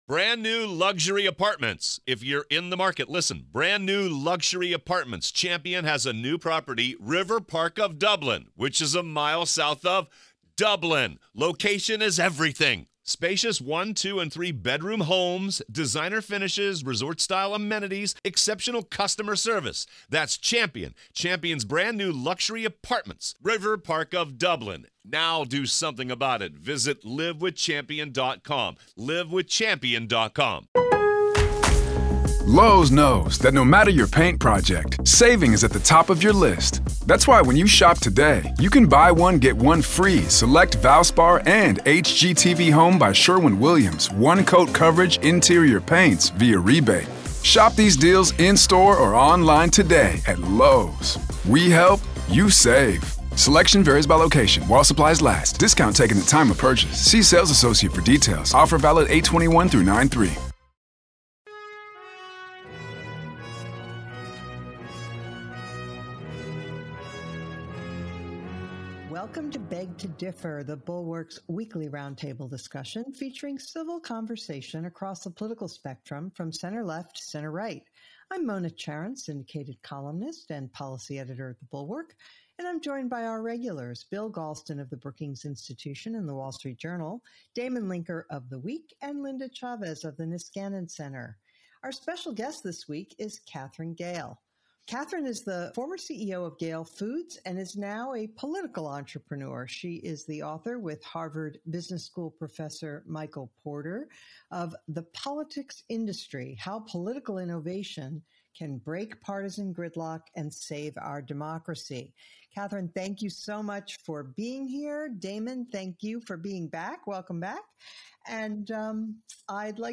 How Political Innovation Can Break Partisan Gridlock and Save Our Democracy” joins the panel for a meaty discussion of voting reform.